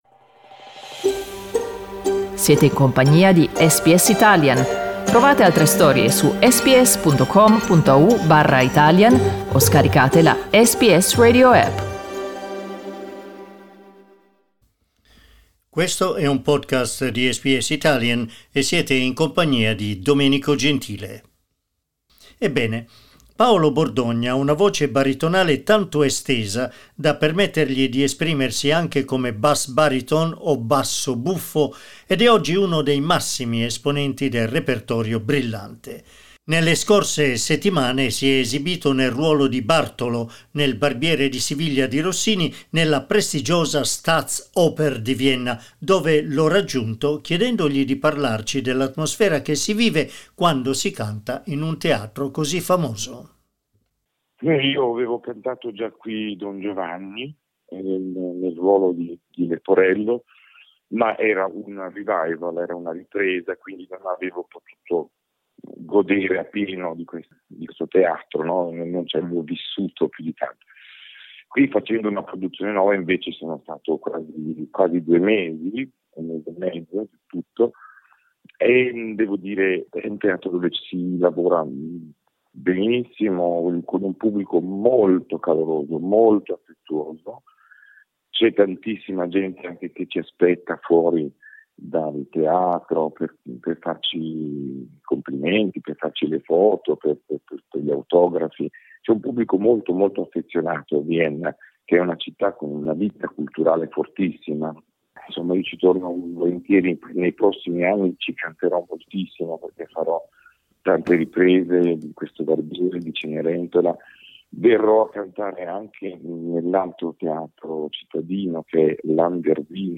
Italian bass-baritone Paolo Bordogna.
Potrebbe interessarti anche Opera Australia 2022: un mega-teatro in più a Sydney, opere, musical e cast stellari In questa conversazione con SBS Italian ci racconta della sua recente esperienza alla Staatsoper di Vienna, dove tornerà a cantare a dicembre nella Cenerentola di Gioachino Rossini. Ascolta l'intervista: LISTEN TO Paolo Bordogna, simbolo dell'opera buffa italiana SBS Italian 08:56 Italian Le persone in Australia devono stare ad almeno 1,5 metri di distanza dagli altri.